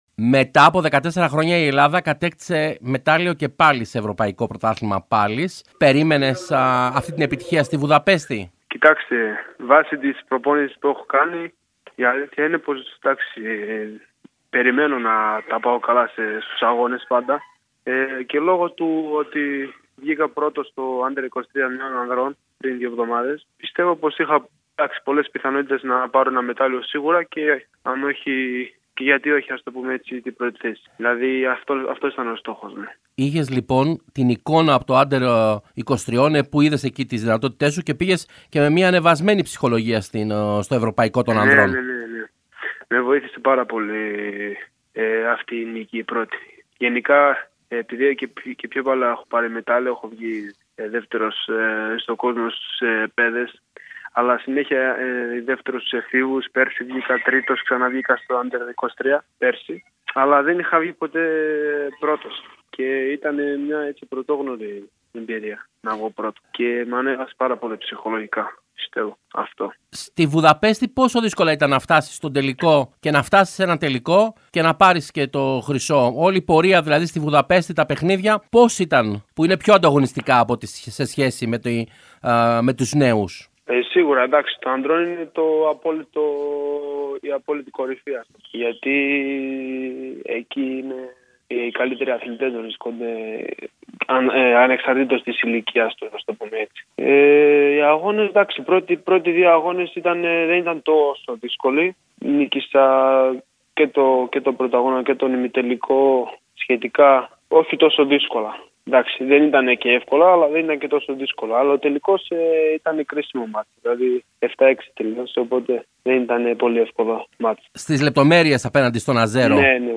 μιλώντας στον «Realfm 97,8» και στην εκπομπή Realsports